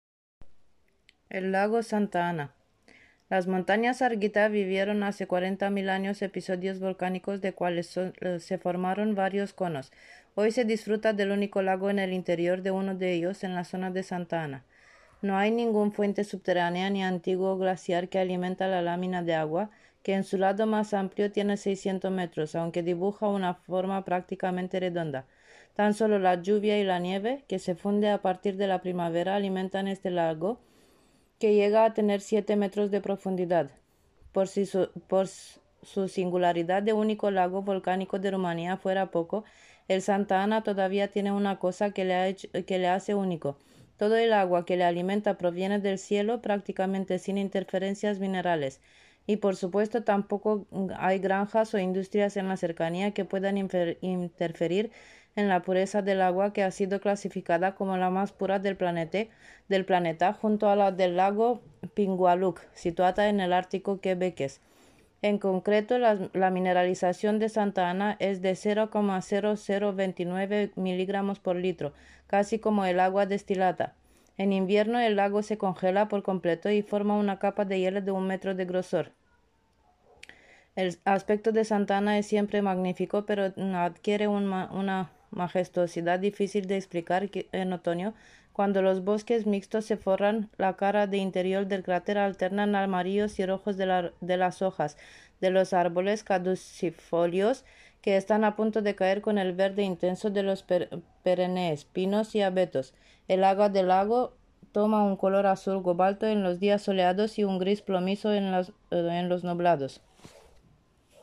Este curso hemos propuesto una actividad a las familias del centro: contar historias de su país o de su zona geográfica y hacerlo en su idioma o con su acento particular.